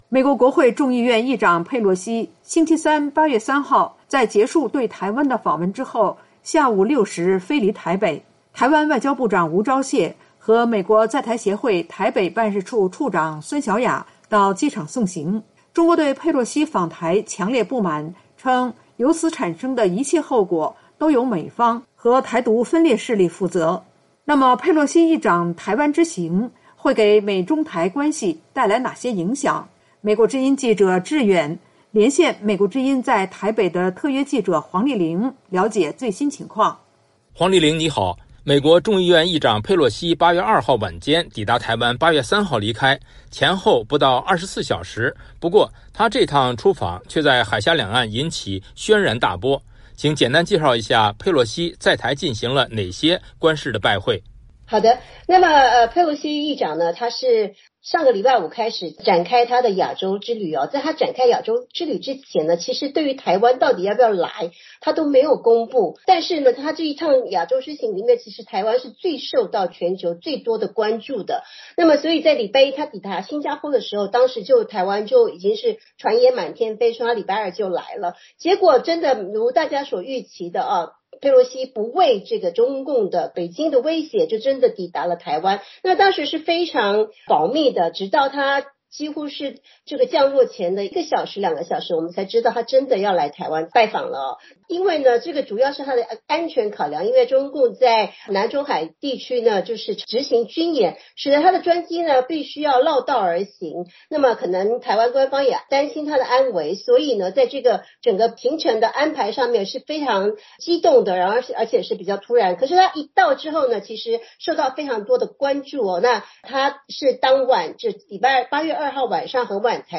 VOA连线：台北记者连线：佩洛西访台打破了一个惯例